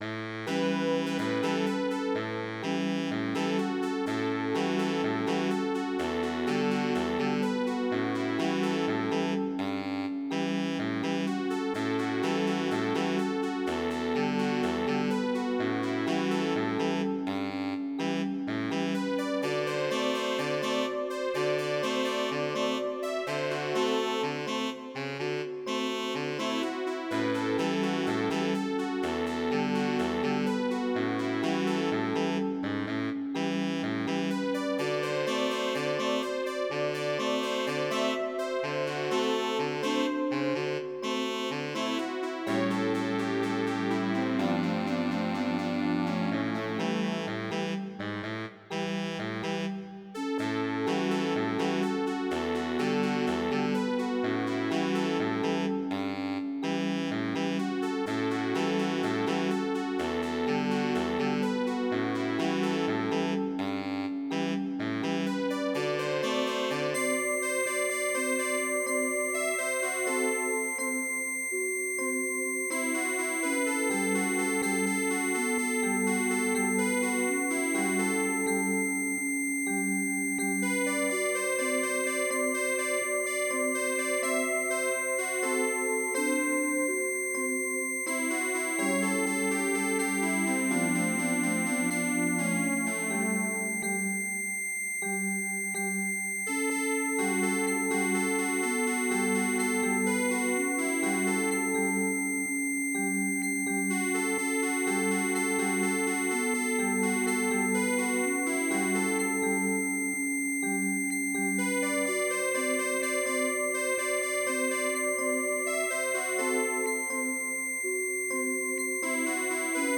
MIDI Music File
2 channels
Type General MIDI